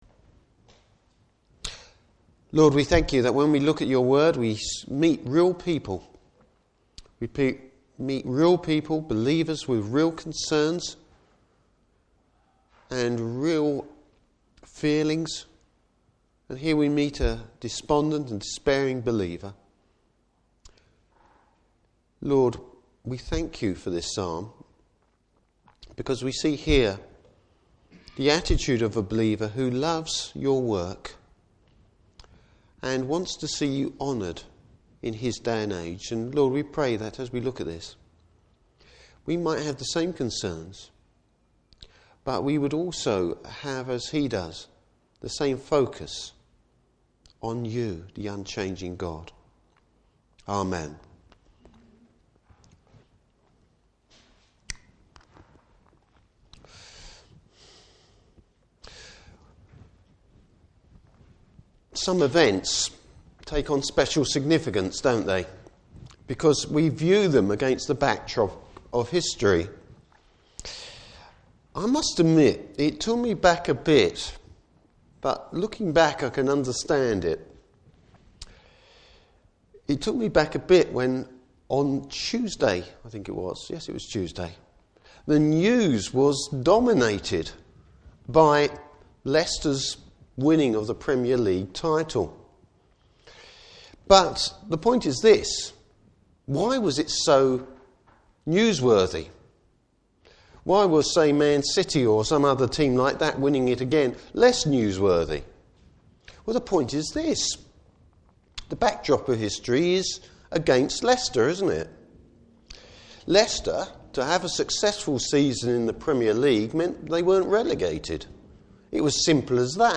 Service Type: Morning Service Bible Text: Psalm 102.